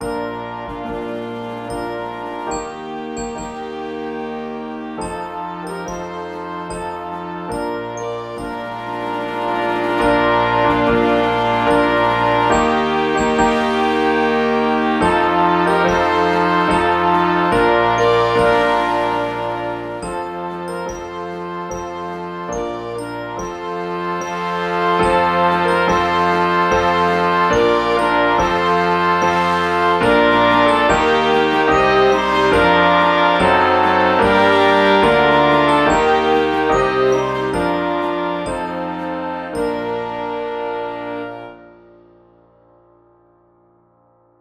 Traditional Trad. Gaudeamus Igitur (Graduation March) Wind Band version
3/4 (View more 3/4 Music)
Bb major (Sounding Pitch) (View more Bb major Music for Wind Band )
Traditional (View more Traditional Wind Band Music)
Gaudeamus_Igitur_Bnd.mp3